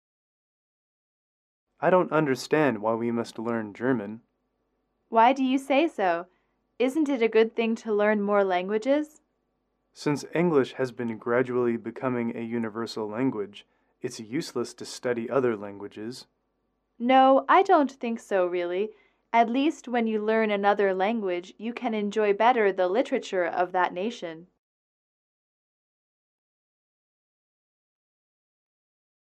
英语口语情景短对话42-4：学习德语(MP3)